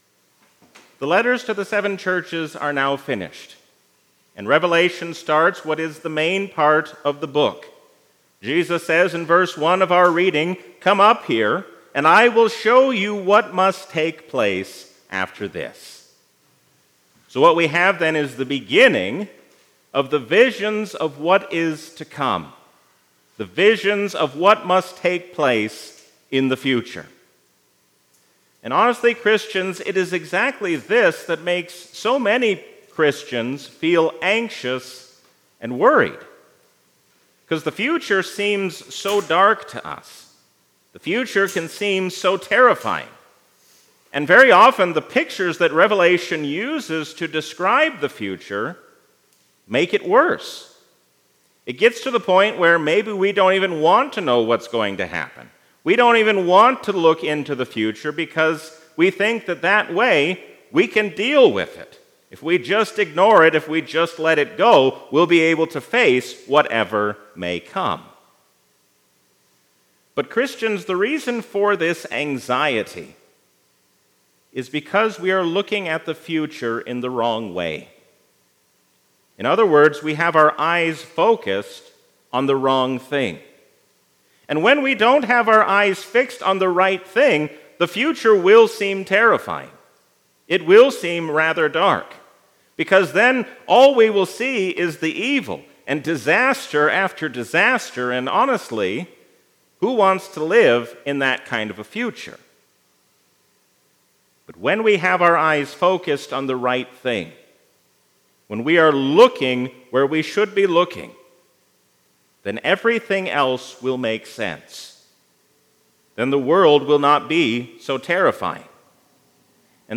Sermons – St. Peter and Zion Lutheran